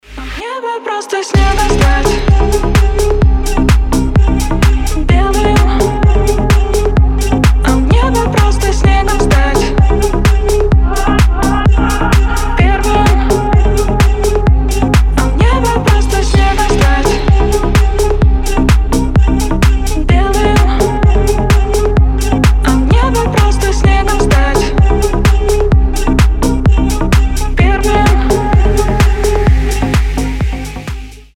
• Качество: 320, Stereo
deep house